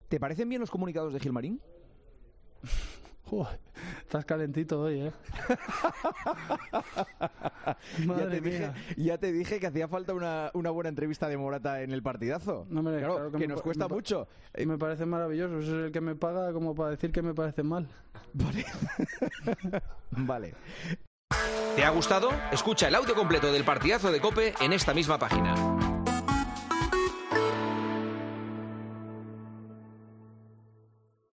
Juanma Castaño aprovechó la visita del delantero a 'El Partidazo de COPE' de este martes para preguntarle al jugador por las jugadas, pero también por las diferentes quejas públicas que ha hecho últimamente su equipo y, en concreto, de Miguel Ángel Gil Marín sobre el Real Madrid y los arbitrajes.